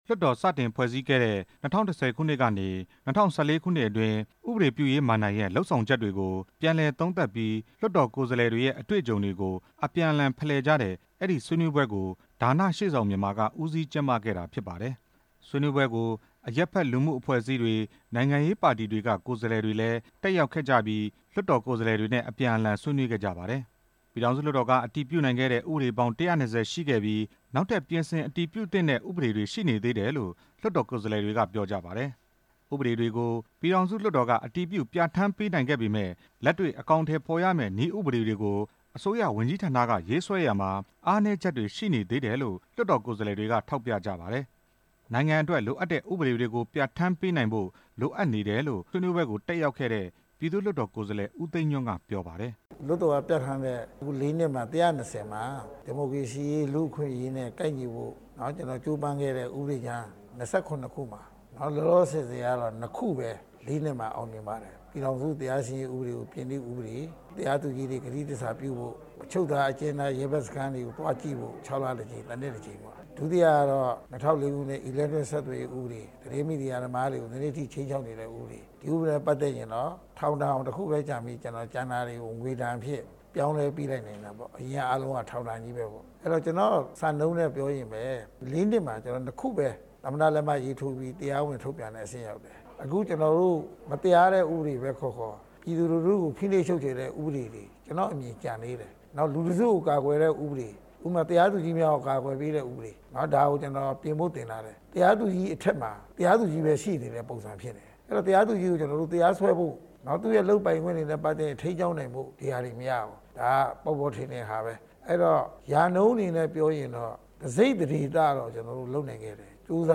ရန်ကုန်မြို့ ဖိုးစိန်လမ်းက Green Hill ဟိုတယ်မှာ ကျင်းပတဲ့ လွှတ်တော်ကိုယ်စားလှယ်တွေရဲ့  ၄ နှစ် တာသက်တမ်းကာလအတွင်း လွှတ်တော် အတွေ့အကြုံ ရှင်းလင်းဆွေးနွေးပွဲမှာ ကိုယ်စားလှယ် ဒေါက်တာ ညိုညိုသင်းက အခုလိုပြောခဲ့တာဖြစ်ပါတယ်။